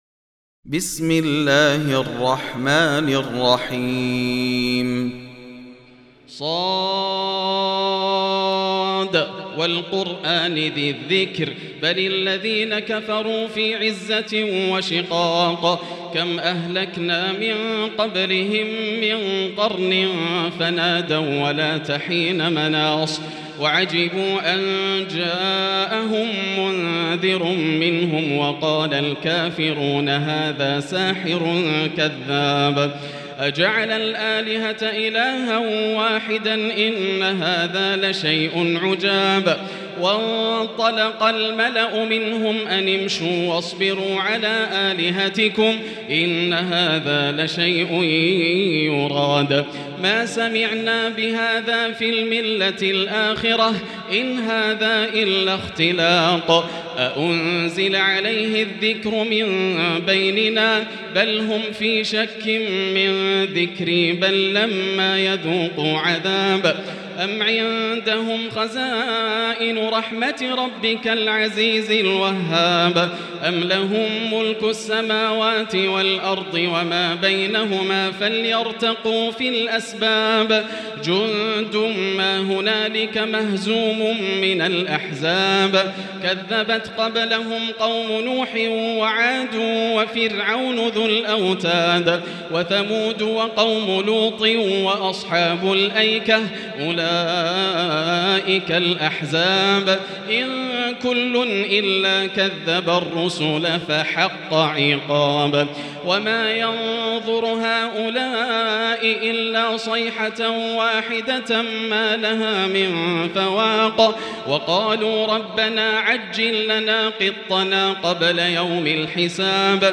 المكان: المسجد الحرام الشيخ: فضيلة الشيخ ياسر الدوسري فضيلة الشيخ ياسر الدوسري ص